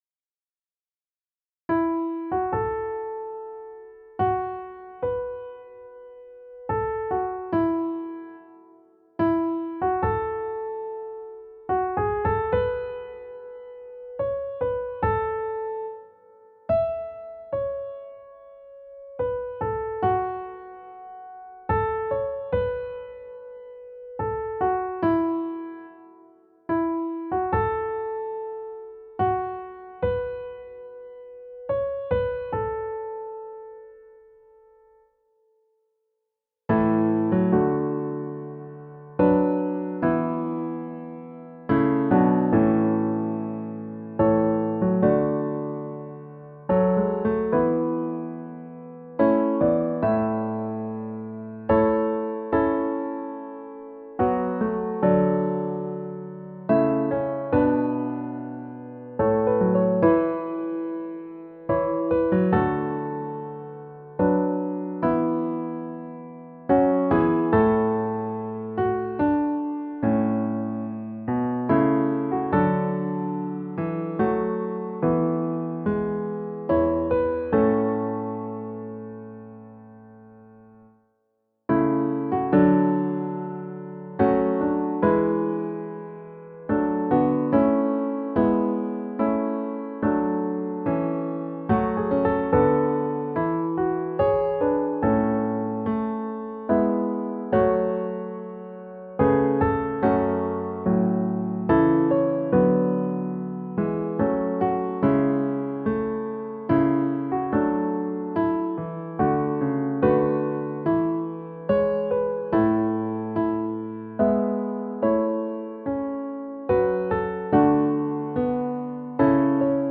• Music Type: Choral
• Voicing: SATB
• Accompaniment: a cappella